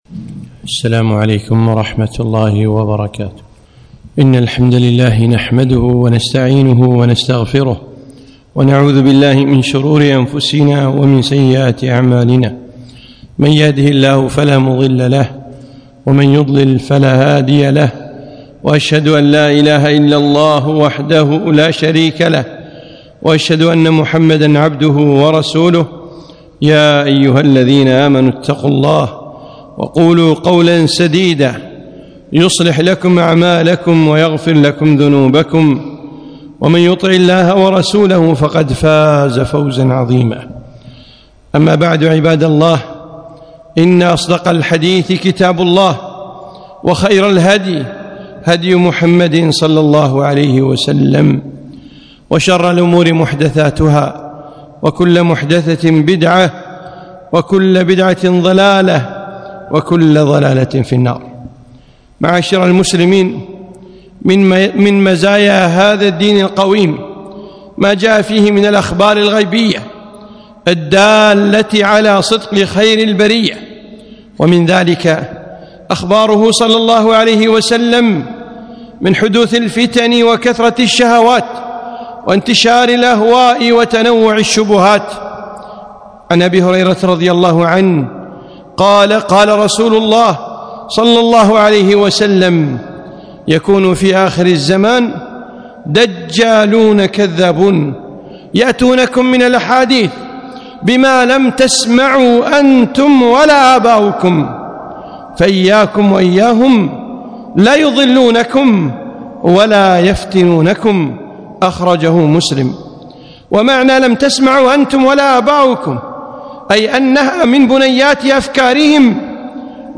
خطبة - التحذير من الخلايا الإرهابية